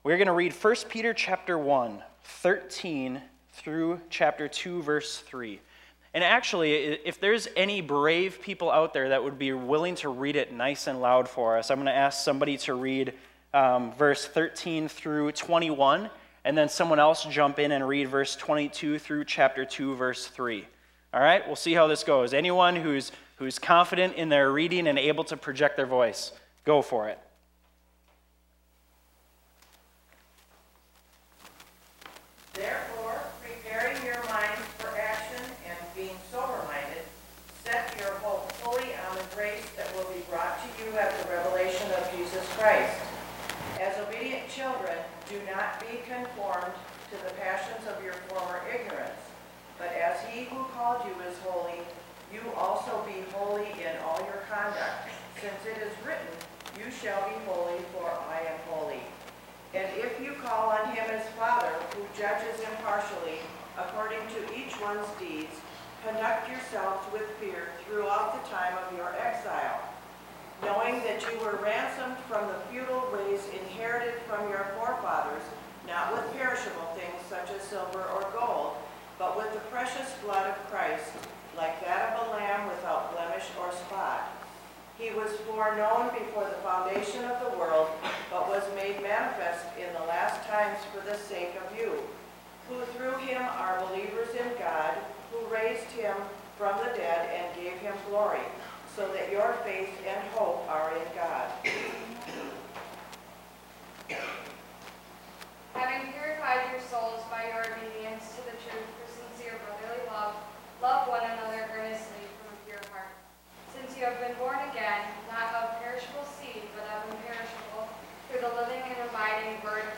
Part 1 Preacher